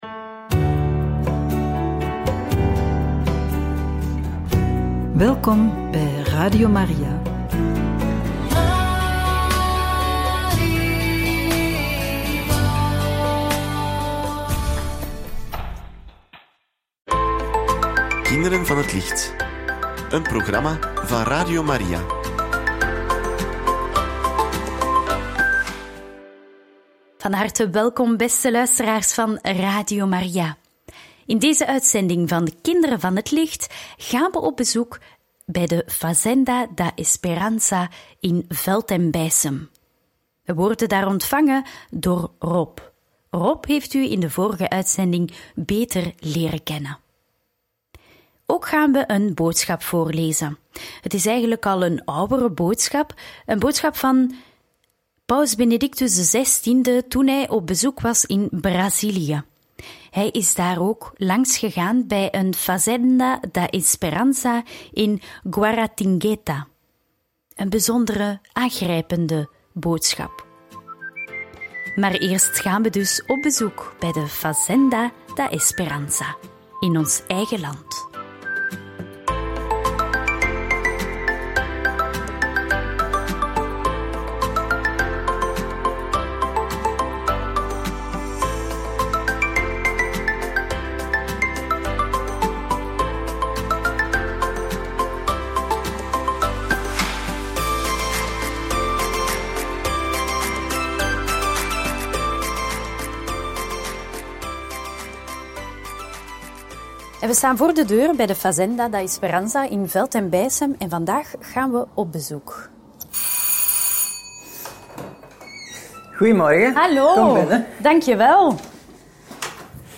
Op bezoek bij de Fazenda in Veltem en toespraak van Paus Benedictus XVI bij zijn bezoek aan de Fazenda in Brazilië! – Radio Maria